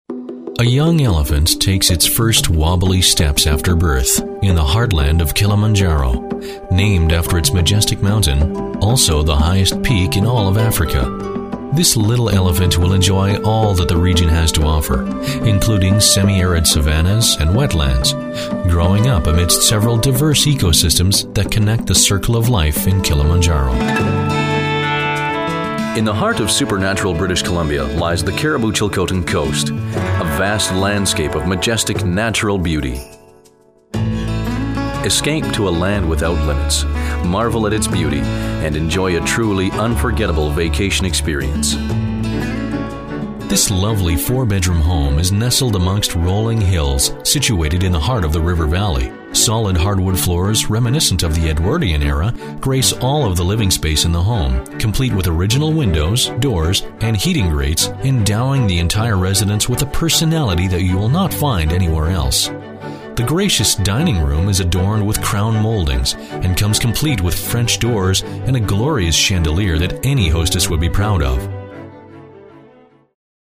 Male
My natural voice is easily described as deep, authoritative or commanding.
Documentary
My Warm, Rich, Natural Sound
1019Narration_demo.mp3